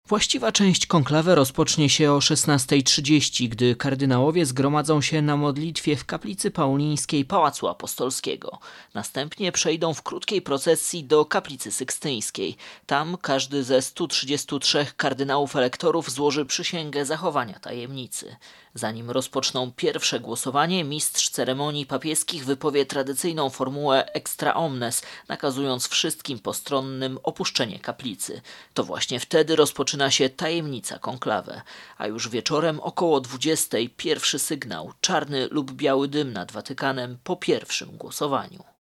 AUDIO: Relacje dotyczące konklawe.
Relacja